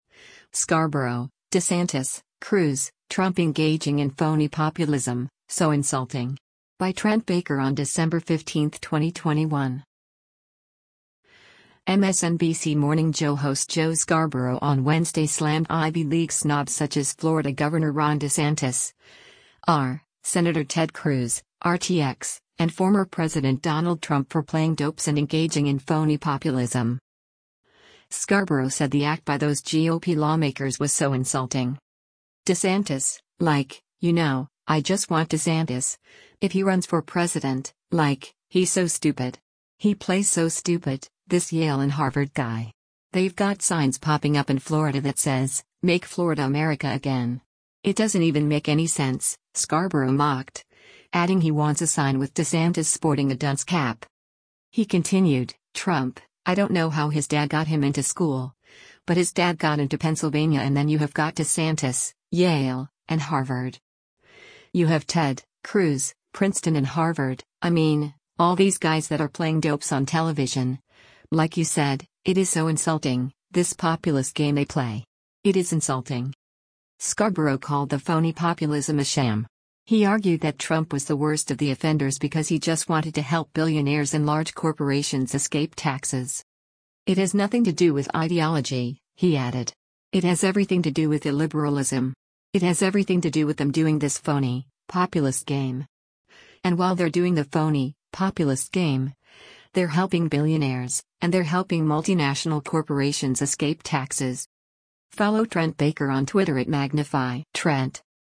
MSNBC “Morning Joe” host Joe Scarborough on Wednesday slammed “Ivy League snobs” such as Florida Gov. Ron DeSantis (R), Sen. Ted Cruz (R-TX) and former President Donald Trump for “playing dopes” and engaging in “phony populism.”